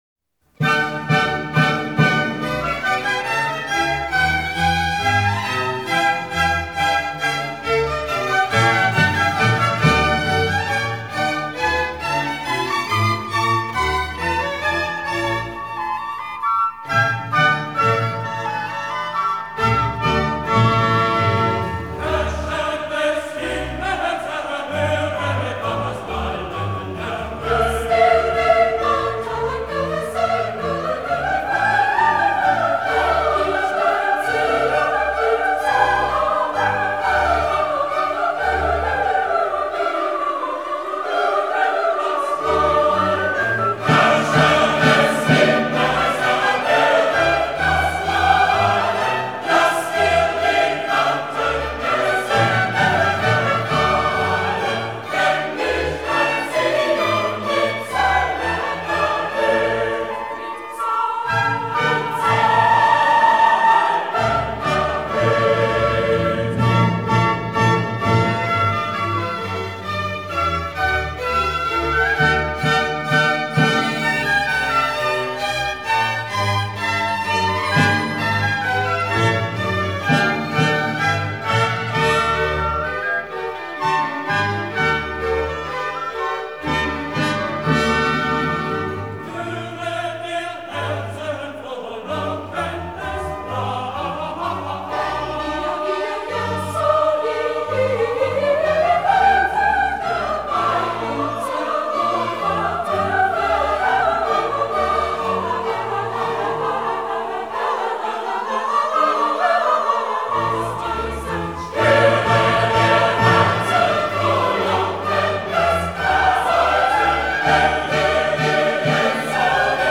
Diese Einspielung vom Vorabend der sogenannten Originalklang-Ära gelang als wolklingende Mixtur aus Besinnung auf barocke Aufführungspraktiken und die große romantische Oratorien-Tradtion.
Die vierte Kantate für den Neujahrstag, fällt auch musikalisch aus dem Rahmen: Erstmals erklingen Hörner und auch die Tonart F-Dur kennzeichnet ein »pastorales« Umfeld: Die Menschheit dankt Gott, daß er seinen Sohn hingegeben hat, uns zu erlösen.
In der Folge paraphrasiert Bach eine früher für einen weltlichen Anlaß komponierte »Echo-Arie« für den geistlichen Zweck: Den Fragen der Gläubigen antwortet jeweils tröstlich aus höchsten Höhen die Stimme des Christuskindes – in der Regel dialogisiert hier ein Sopran aus dem Chor mit der Solistin. Umrahmt wird die Arie von zwei orchesterbegleiteten Rezitativen mit Baß-Solo, in die kunstvoll Choralzitate des Sopran »eingelassen« sind.
Evangelist (Tenor)